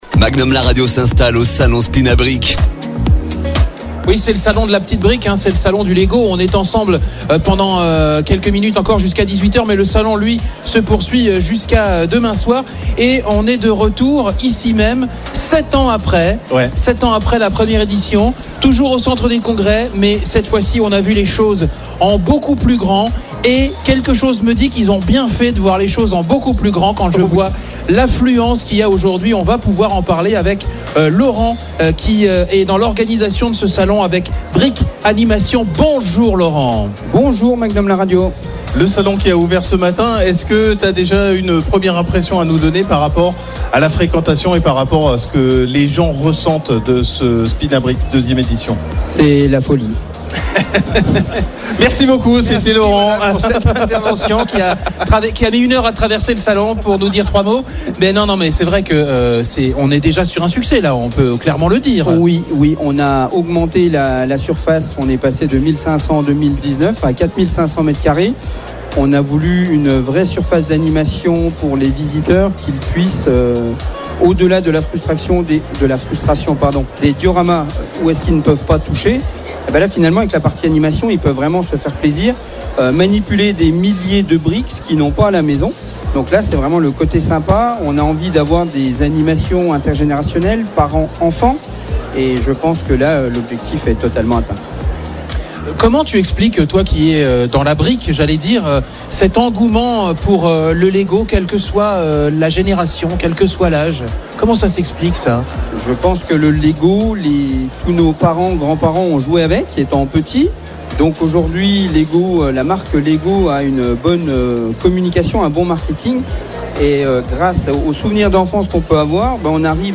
Emission spéciale en direct du salon SPINABRICKS au centre des congrès d'Épinal
Interview